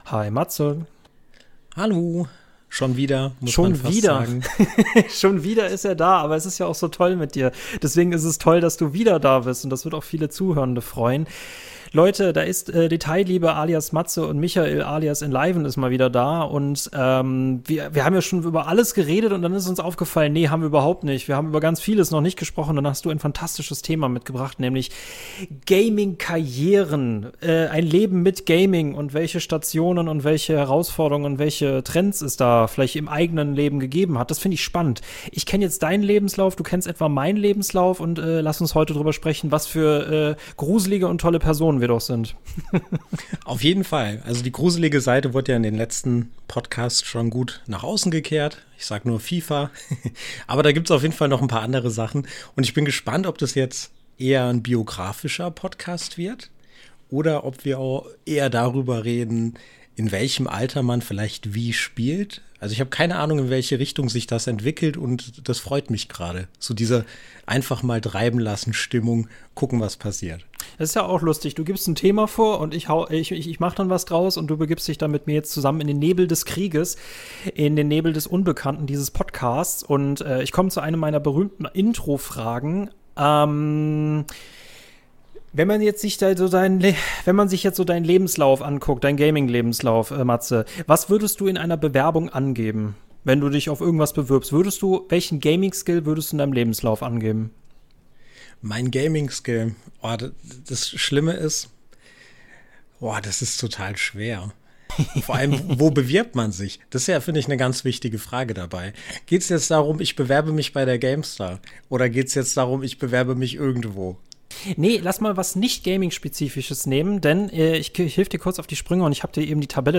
Das und vieles mehr erfahrt in einer entspannten Runde voller Nostalgie und toller Anekdoten.